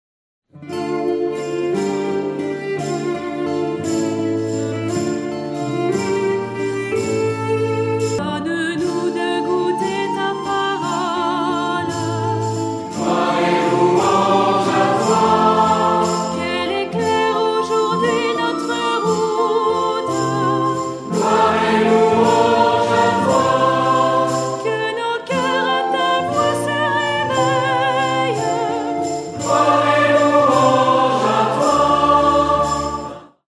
Paroles : M. Scouarnec – Musique : Jo Akepsimas